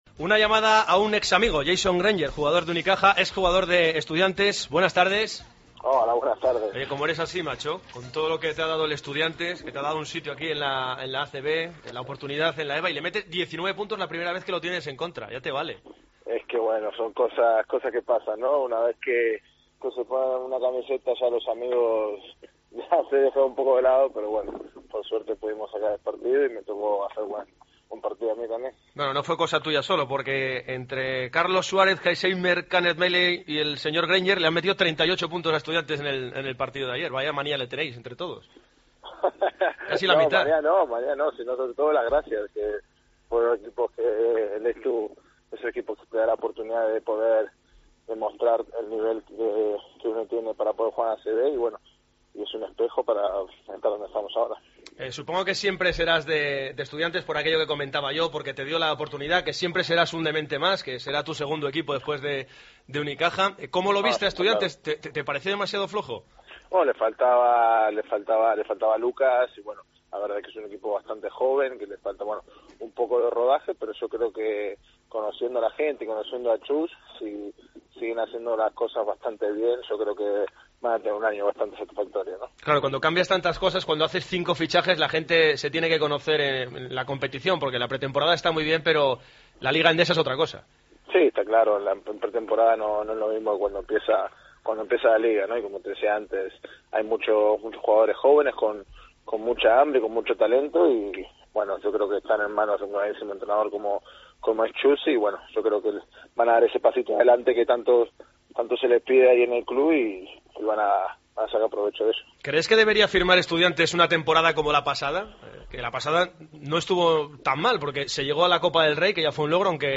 AUDIO: El ex jugador del Estudiantes y ahora en el Unicaja repasó en Deportes Cope la actualidad de la ACB.